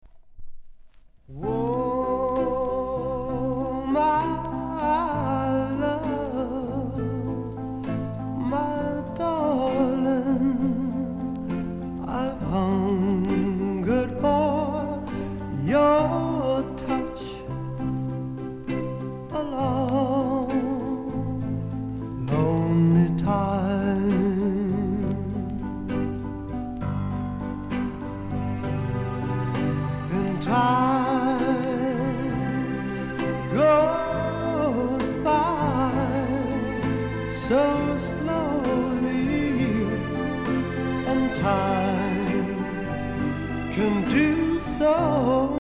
¥ 1,100 税込 関連カテゴリ SOUL/FUNK/etc...